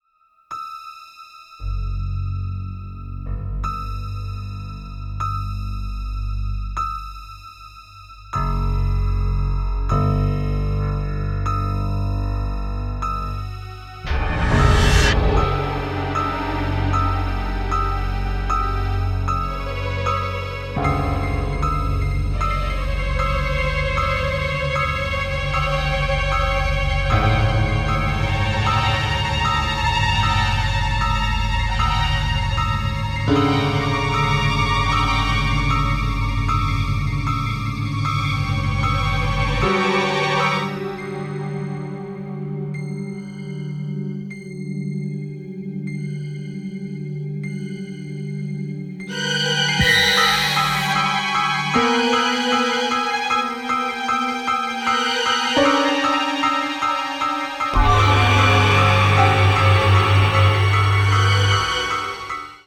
Using the Synclavier